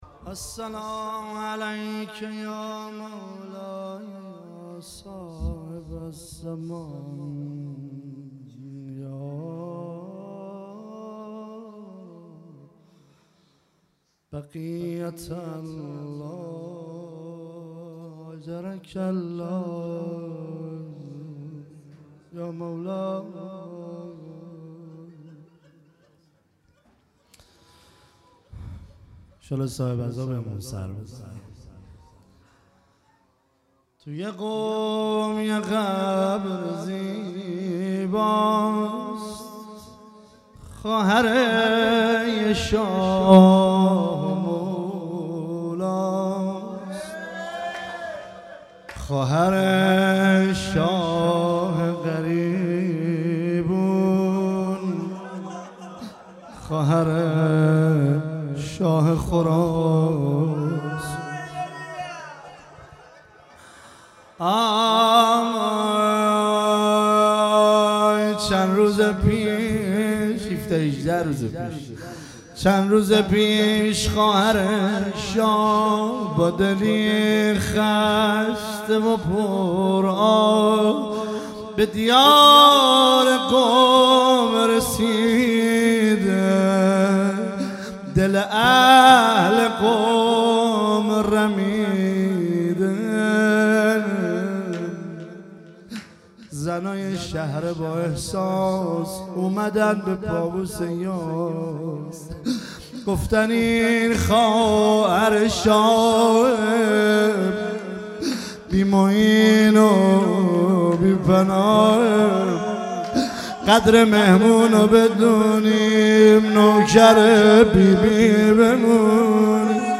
روضه - توی قم یه قبر زیباست